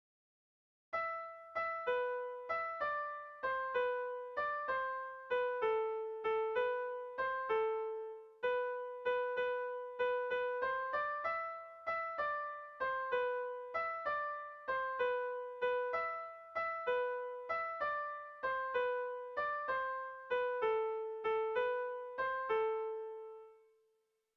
Kontakizunezkoa
ABD..